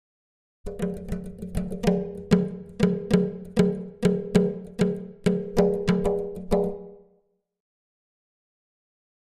Drums Exotic Percussion Beat Version C - Unison Duet